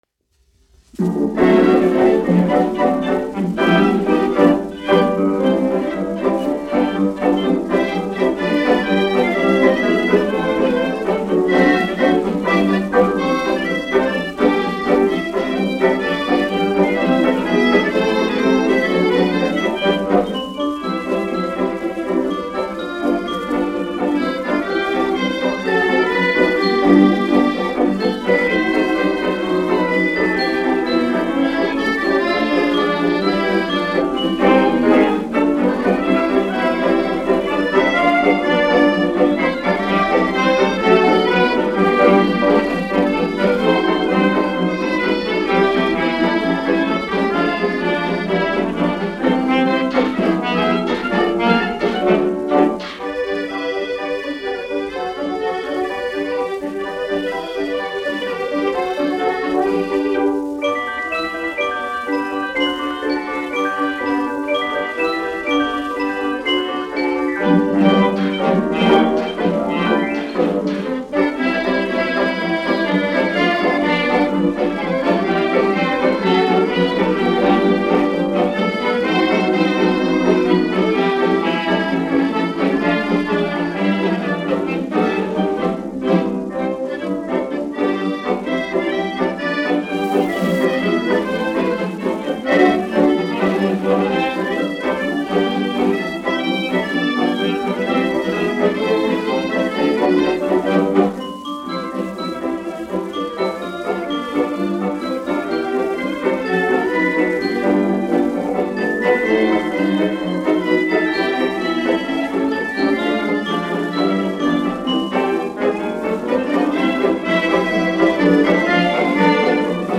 1 skpl. : analogs, 78 apgr/min, mono ; 25 cm
Fokstroti
Deju orķestra mūzika
Skaņuplate